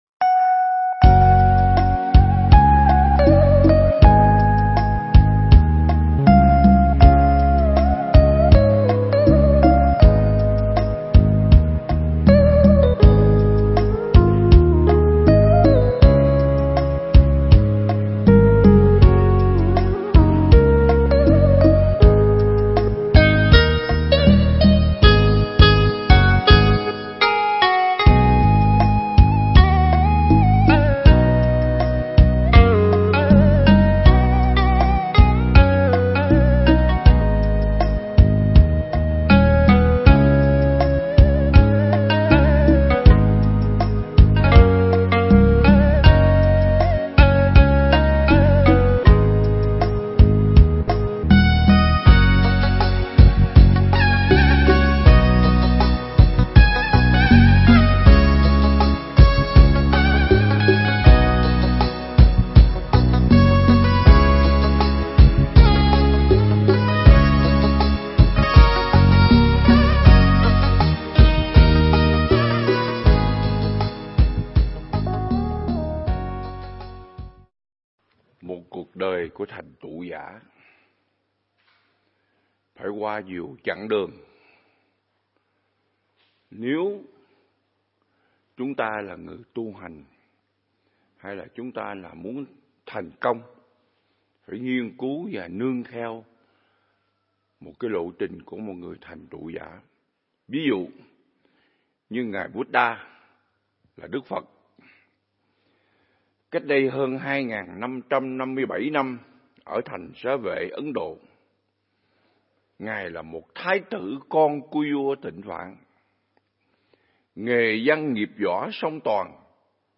Pháp thoại
giảng tại Viện Nghiên Cứu Và Ứng Dụng Buddha Yoga Việt Nam (Hồ Tuyền Lâm, Đà Lạt)